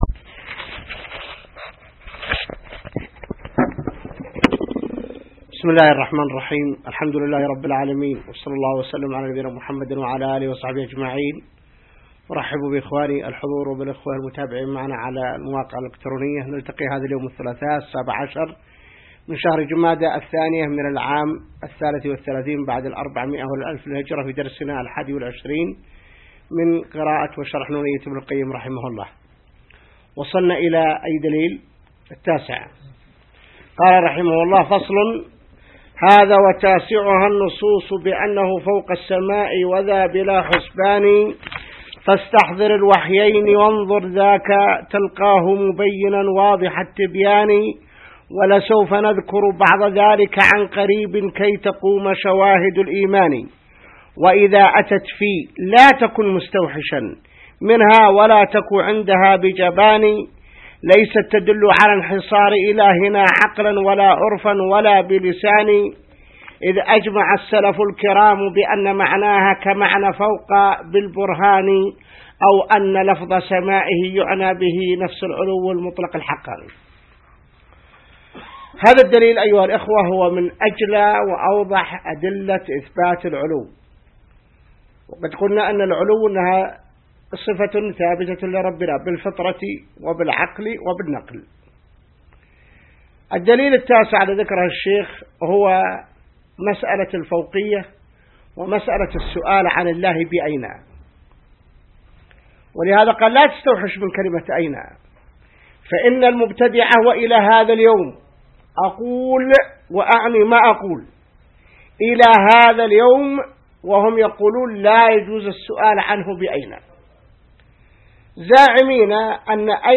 الدرس 21 من شرح نونية ابن القيم | موقع المسلم